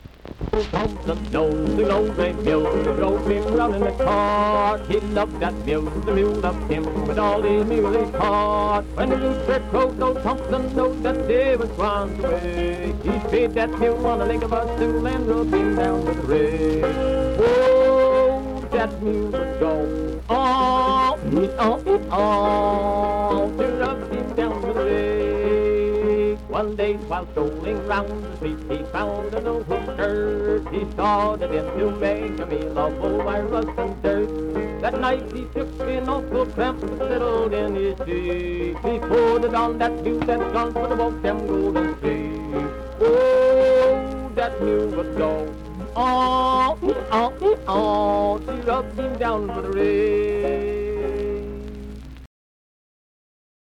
Vocal performance accompanied by banjo.
Minstrel, Blackface, and African-American Songs, Humor and Nonsense
Voice (sung), Banjo
Wood County (W. Va.), Vienna (W. Va.)